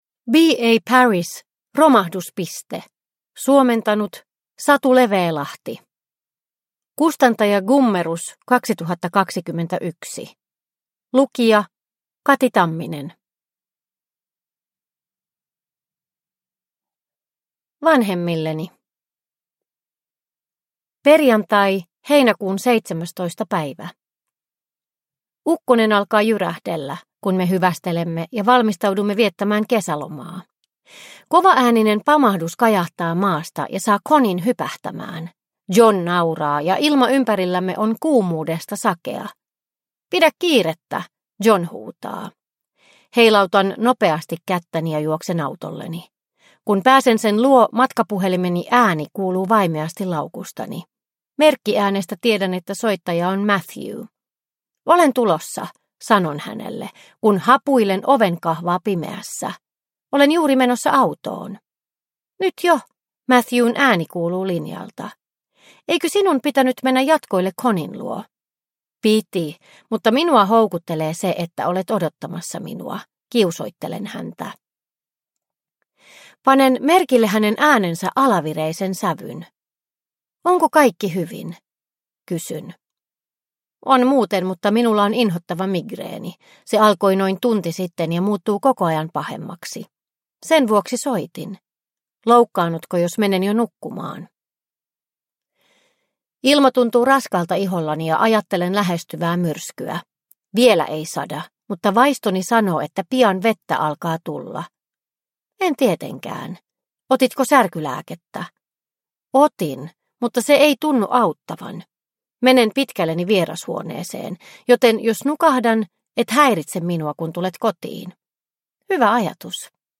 Romahduspiste – Ljudbok – Laddas ner